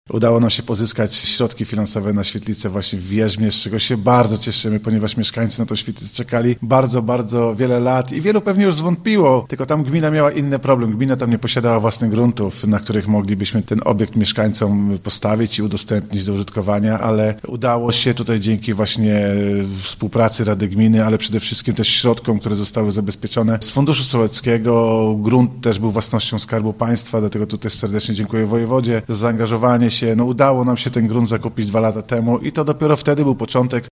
– Nie było to łatwe, bo nie mieliśmy ani działki, ani pieniędzy – przyznaje Bartłomiej Kucharyk, wójt gminy Przytoczna: